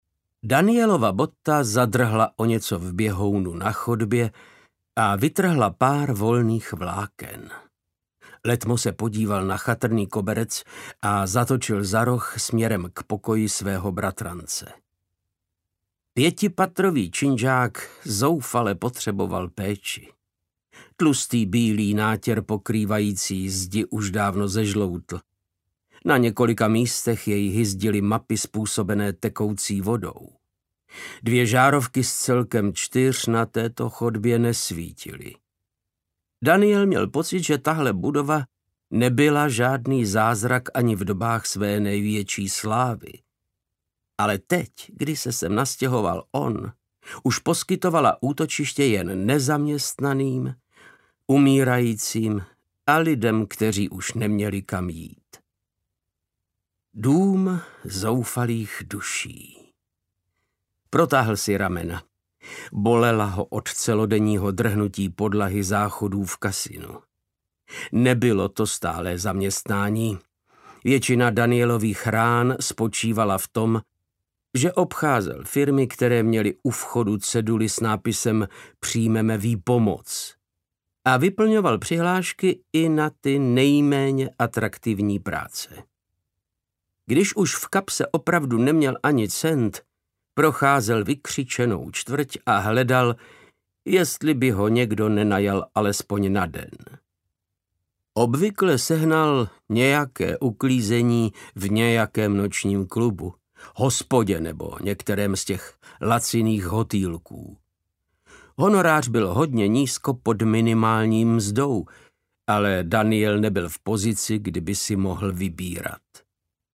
Tajemství sídla Craven Manor audiokniha
Ukázka z knihy
• InterpretVáclav Knop